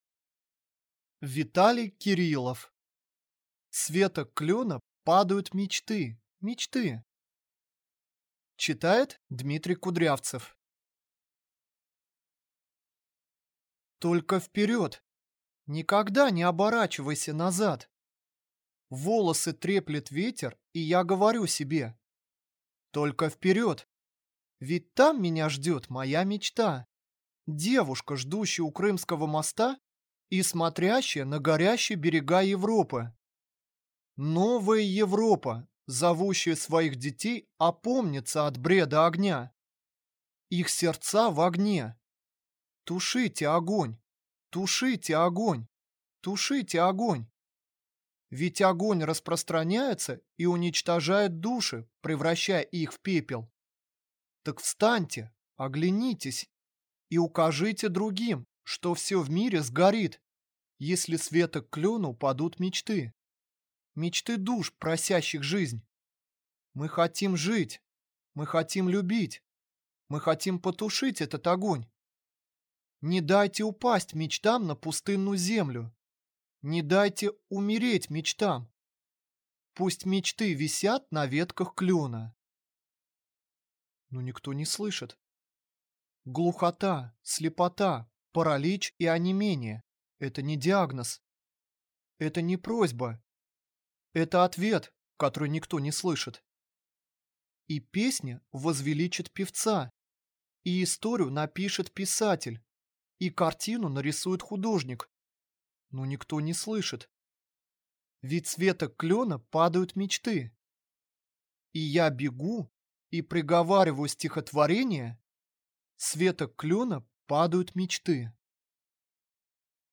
Аудиокнига С веток клёна падают мечты, мечты!